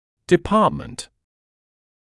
[dɪ’pɑːtmənt][ди’паːтмэнт]факультет; кафедра; отделение; департамент
department.mp3